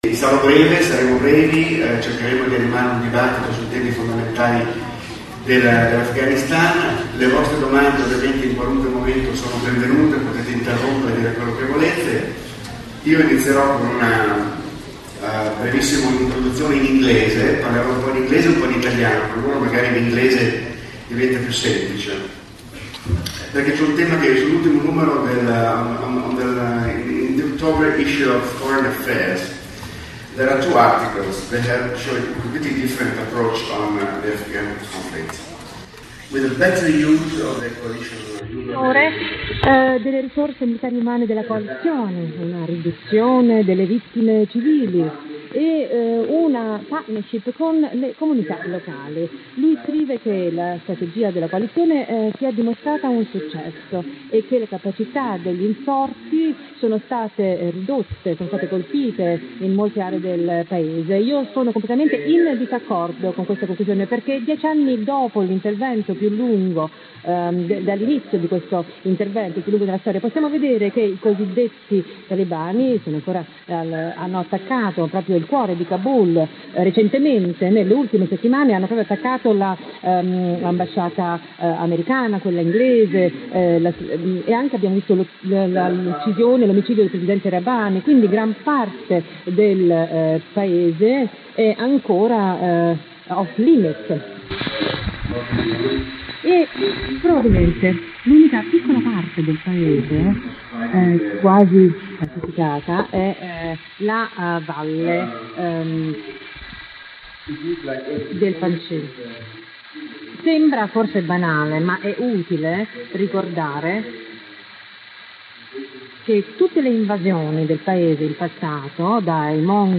INTERNAZIONALE A FERRARA 2011 A FGHANISTAN.